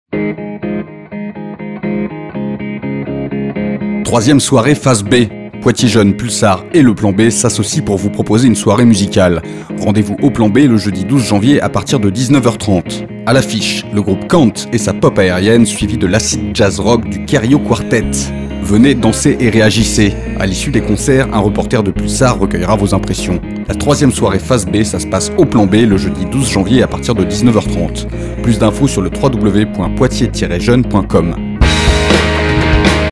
spot radio de promotion de la soirée et l’émission zazou spécialement consacrée à la soirée.